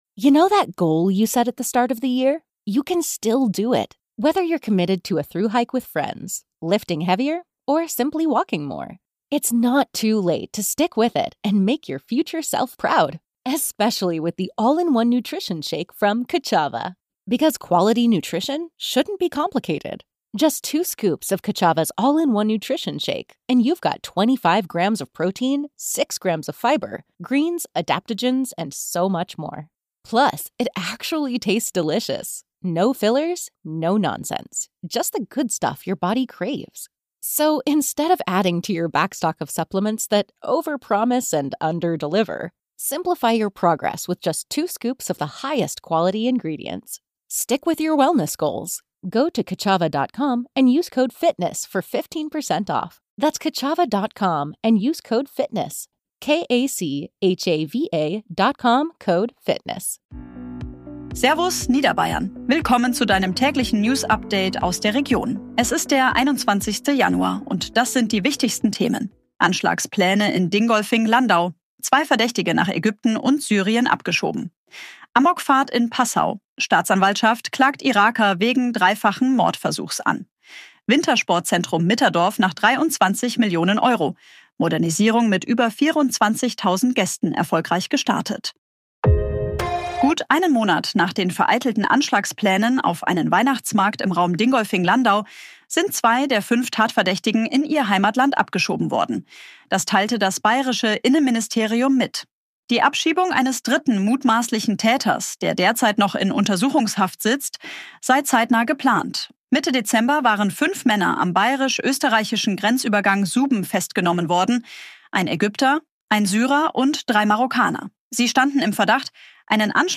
Tägliche Nachrichten aus deiner Region
Dein tägliches News-Update